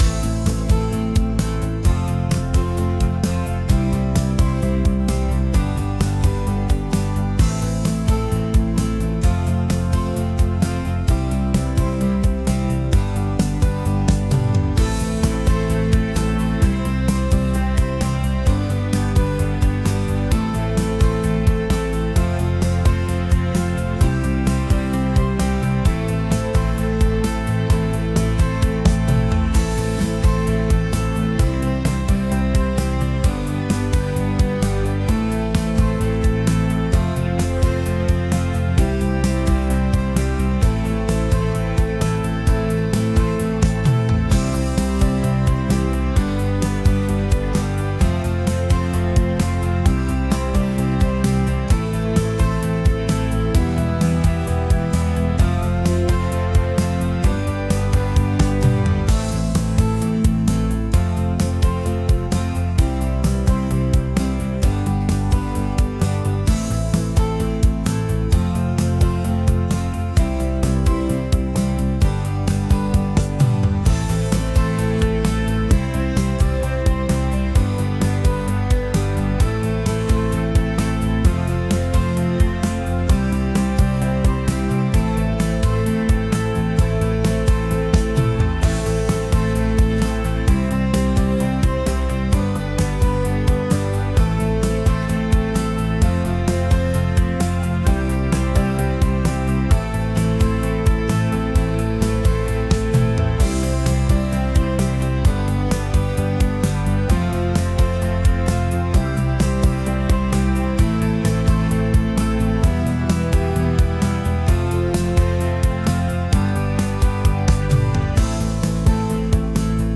「明るい」